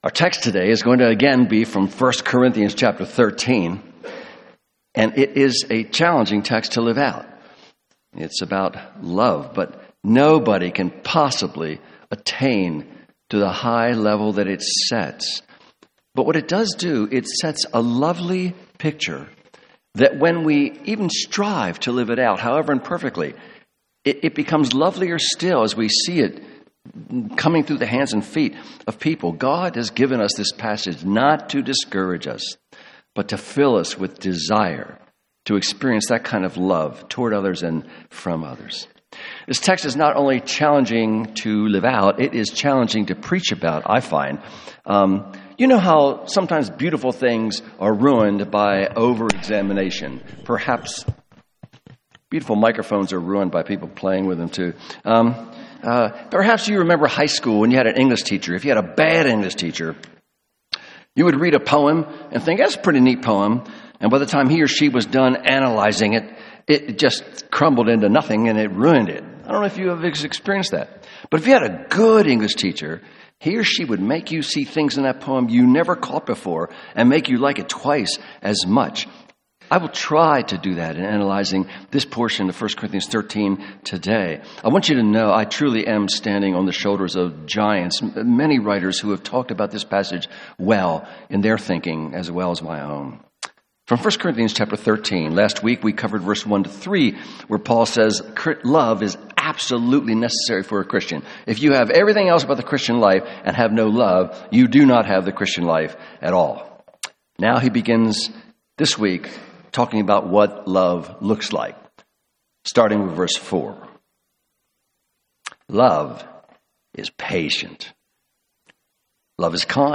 Sermon Outline 1.